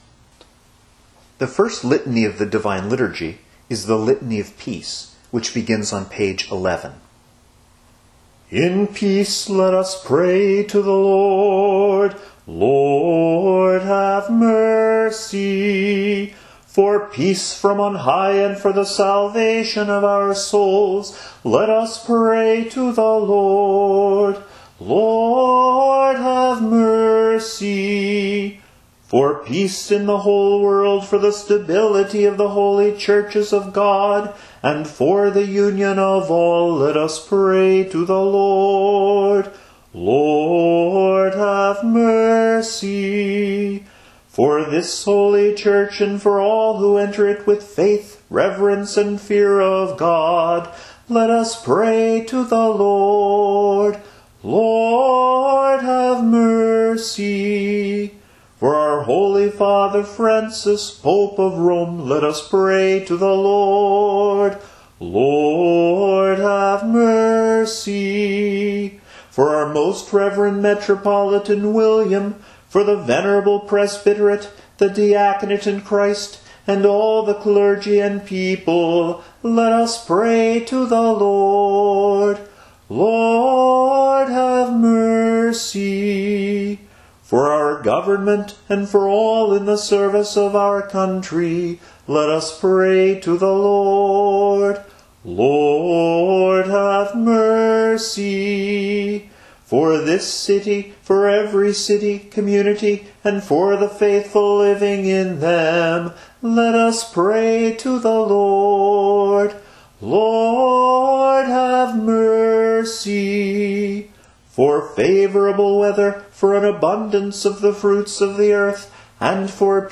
As with the Small Litany, the deacon intones petitions for praying, ending on the tonic pitch, do.
These responses alternate - first one is sung, then the other.